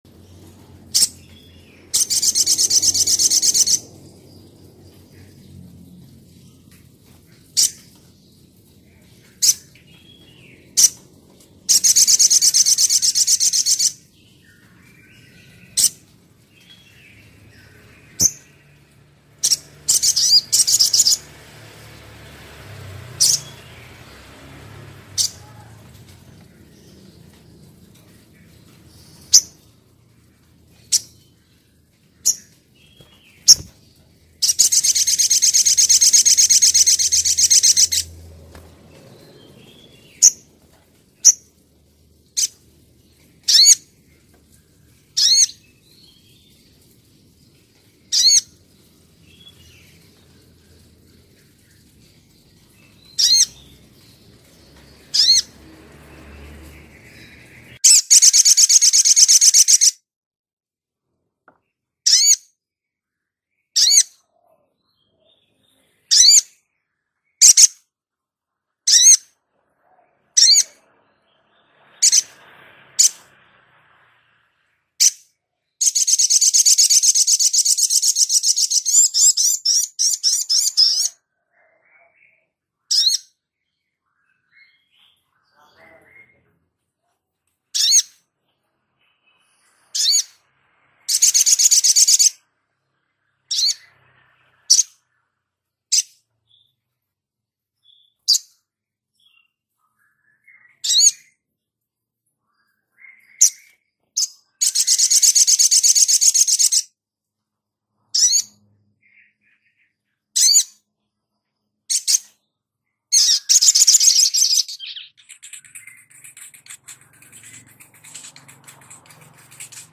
Suara Burung Sogon Jantan
Kategori: Suara burung
Keterangan: Suara panggilan sogok ontong jantan - suara sogon jantan gacor - kicau burung...
suara-burung-sogon-jantan-id-www_tiengdong_com.mp3